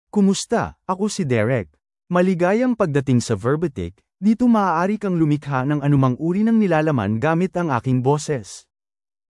DerekMale Filipino AI voice
Derek is a male AI voice for Filipino (Philippines).
Voice sample
Male